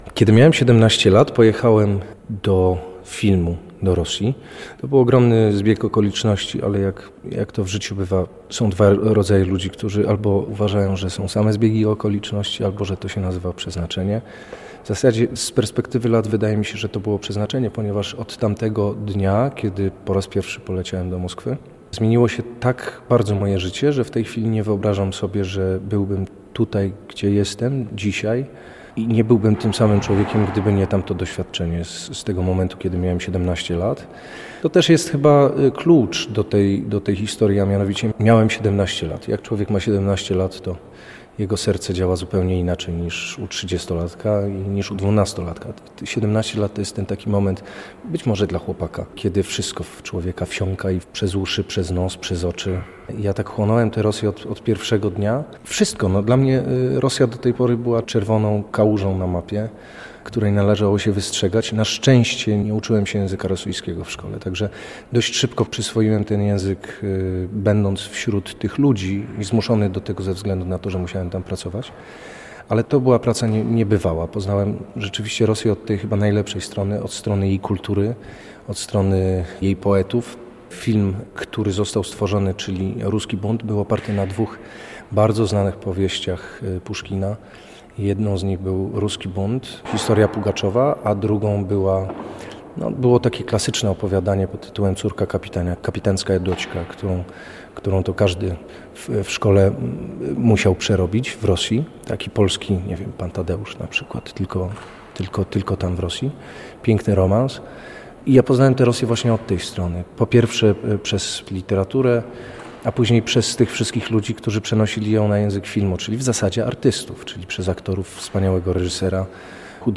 W 2009 roku dodatkowo zorganizował wyprawę na Syberię, która trwała 63 dni i jednym z jej celów było wsparcie polskich rodzin mieszkających tysiące kilometrów od Polski. Mateusz Damięcki, aktor, opowiada o swojej miłości do Rosji i Rosjan.